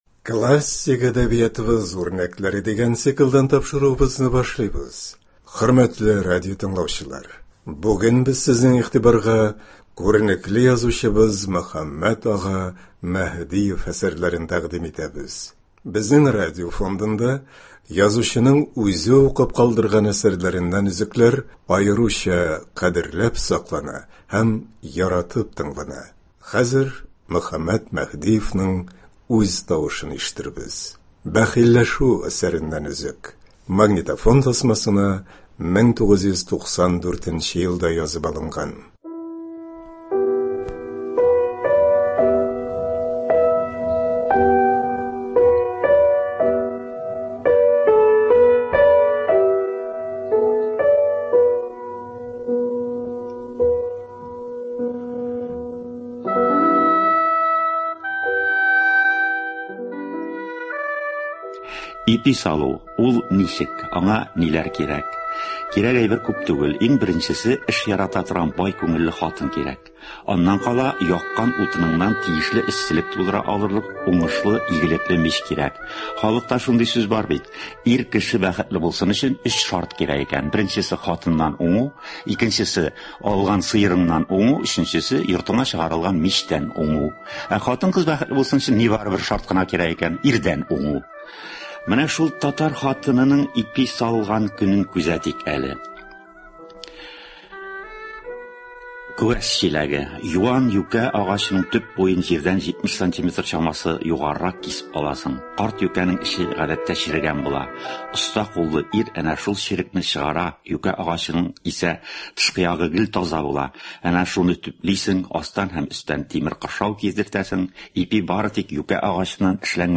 Безнең радио фондында язучының үзе укып калдырган әсәрләреннән өзекләр аеруча кадерләп саклана һәм яратып тыңлана. Хәзер Мөхәммәт Мәһдиевнең үз тавышын ишетербез.
“Бәхилләшү” әсәреннән өзекләр яңгырый. Магнитофон тасмасына Татарстан радиосы студиясендә 1994-1995 елларда язып алынган.